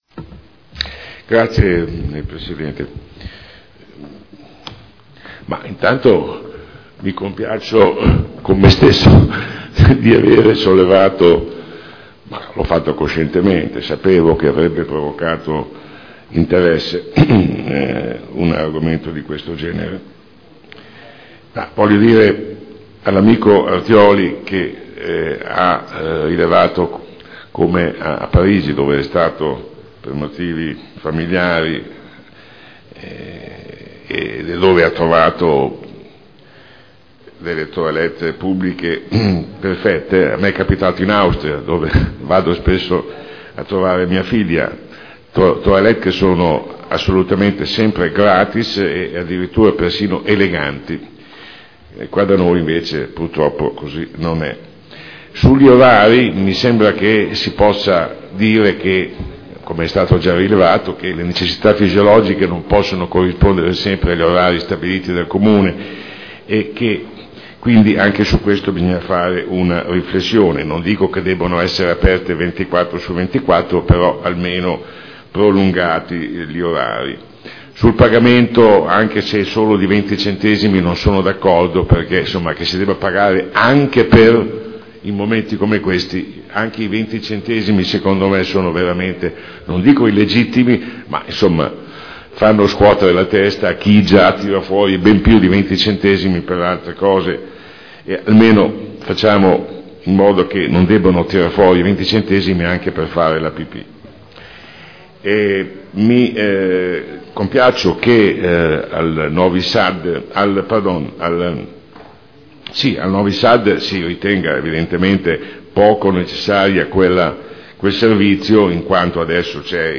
Seduta del 10/09/2012 dibattito su Interrogazione del consigliere Bellei (PdL) avente per oggetto: “Assurdi gli orari dei bagni pubblici. Com’è possibile dover pagare per accedervi?” (presentata il 21 maggio 2012 – in trattazione il 10.9.2012) e Interrogazione del consigliere Bellei (PdL) avente per oggetto: “Situazione disastrosa dei gabinetti pubblici, indegna della nostra città” (presentata il 9 luglio 2012 – in trattazione il 10.9.2012)